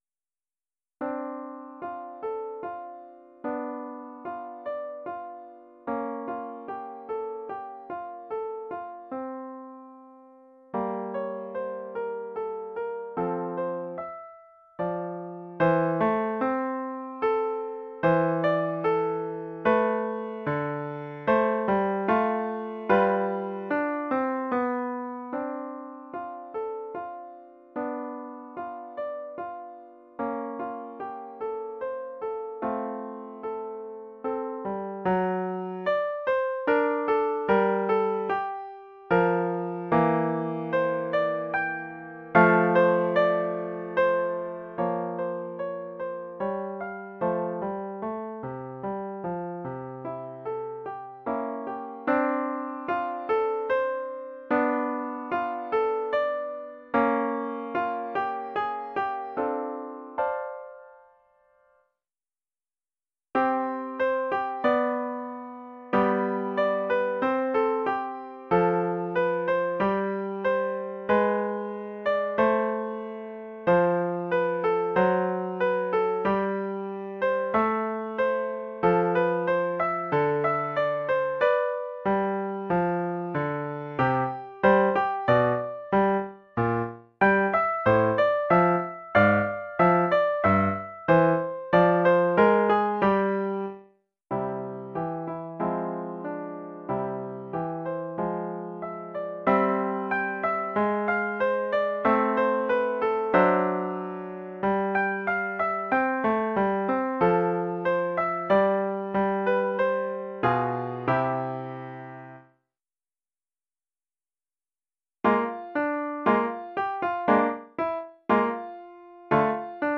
Collection : Piano
Oeuvre en 4 mouvements
pour piano solo.
La pièce est structurée en quatre mouvements.